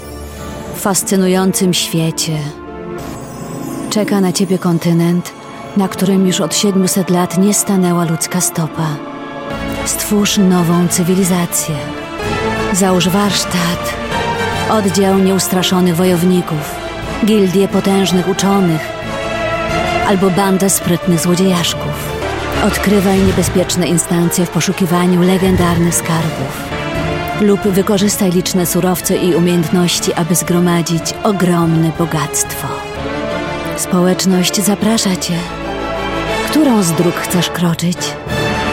Sprecherin polnisch und Schauspielerin.
Sprechprobe: Sonstiges (Muttersprache):
I´m a professional native polish actress voice talent.